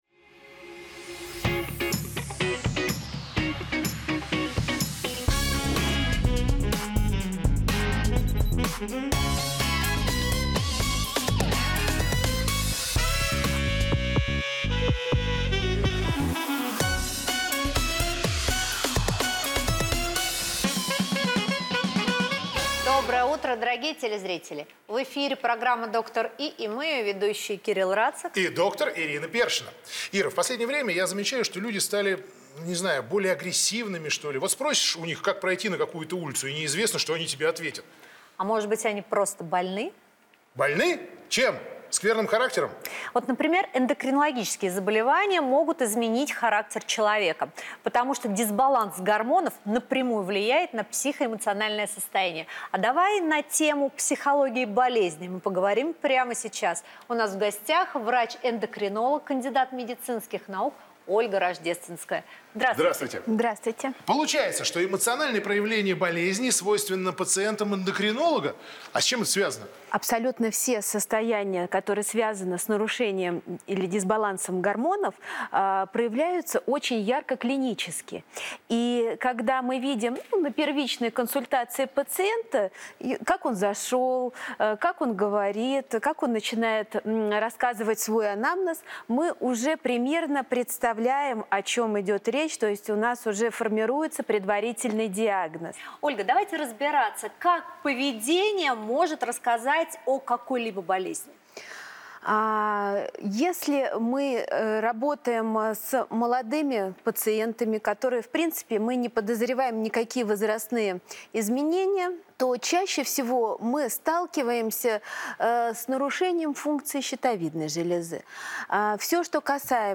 Эндокринные заболевания влияют на характер человека Дата: 17.08.2022 Источник: телеканал ТВЦ.
Медиатека. 00:00:15 - МУЗЫКАЛЬНАЯ ЗАСТАВКА.